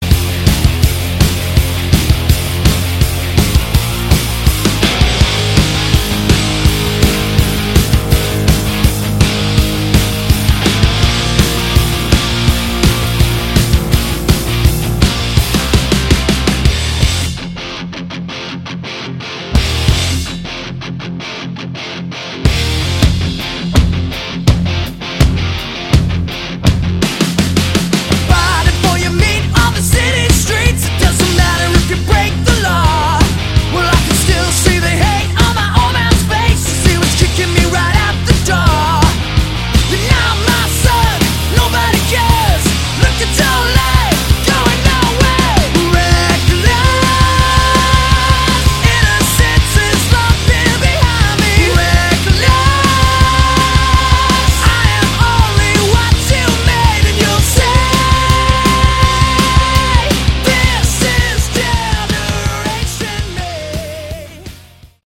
Category: Hard Rock
vocals
bass
guitar
drums